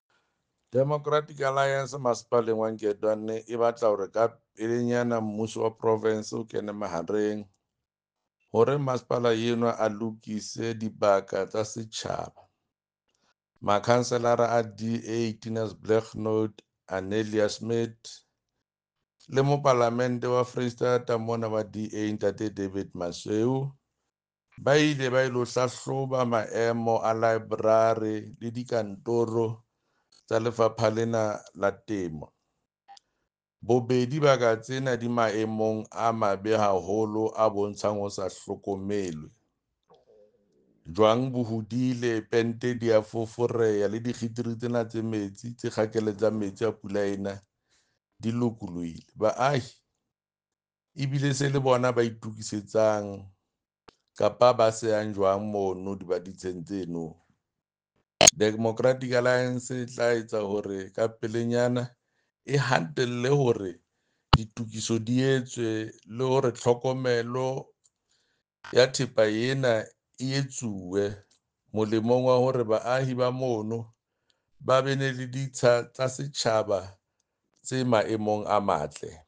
Sesotho soundbite by Jafta Mokoena MPL.